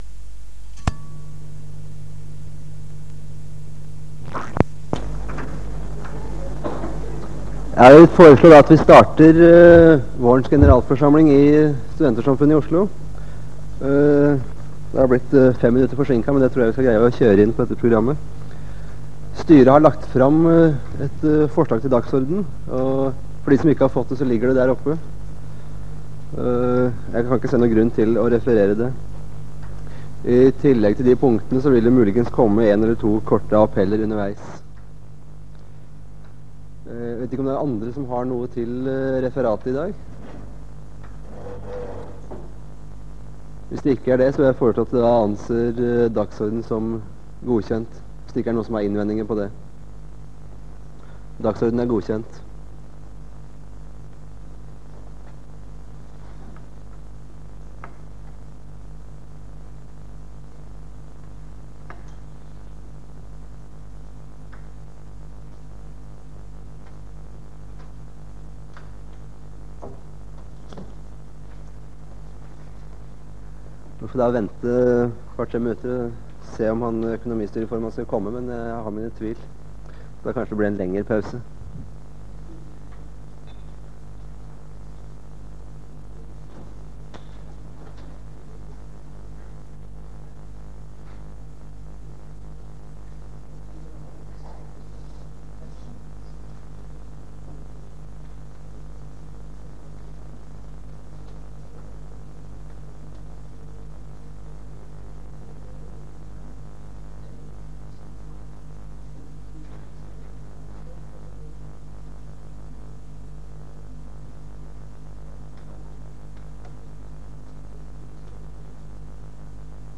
Det Norske Studentersamfund, Generalforsamling, 05.05.1978